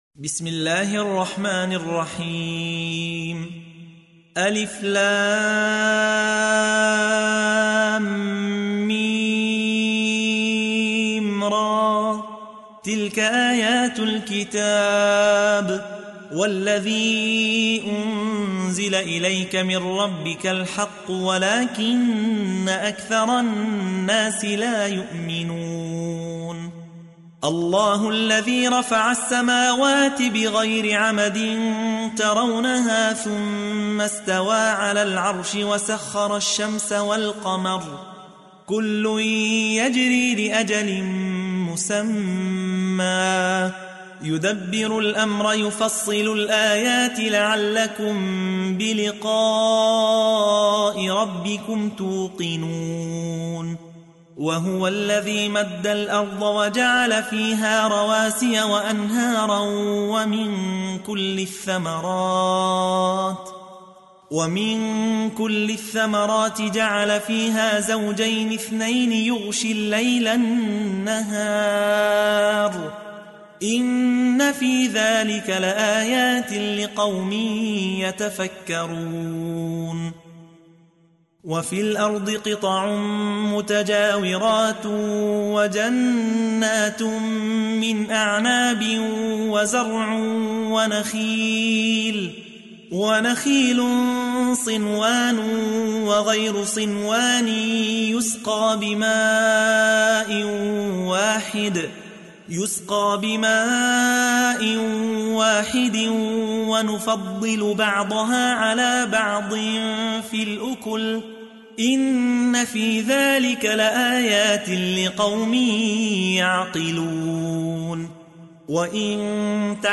تحميل : 13. سورة الرعد / القارئ يحيى حوا / القرآن الكريم / موقع يا حسين